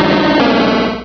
pokeemerald / sound / direct_sound_samples / cries / stantler.aif
-Replaced the Gen. 1 to 3 cries with BW2 rips.